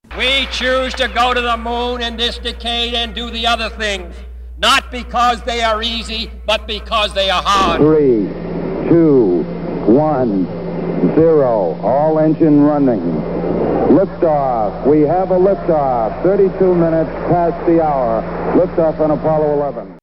(At Rice Stadium in Houston, Texas on September 12, 1962, President John F. Kennedy)